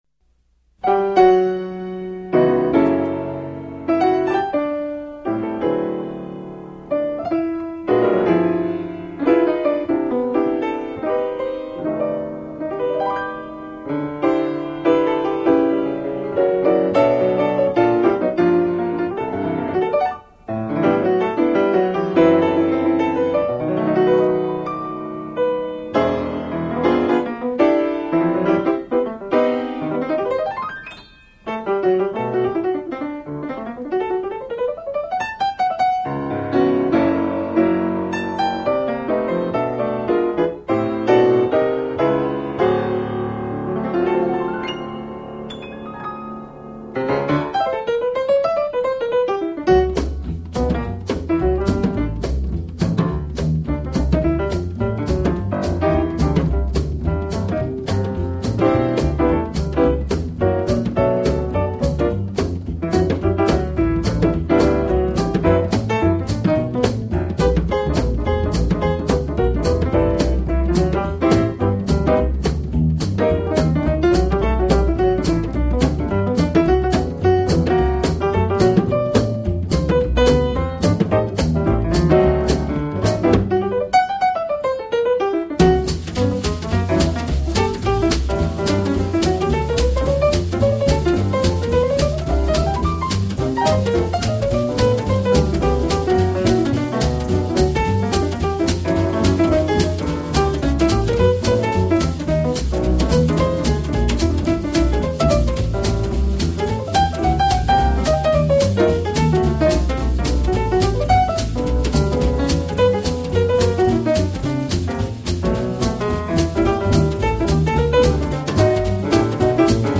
唱片分类：JAZZ